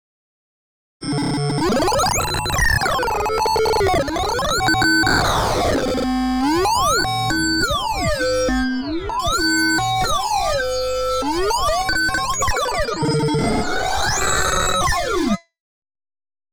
CHAOS-NOISE.wav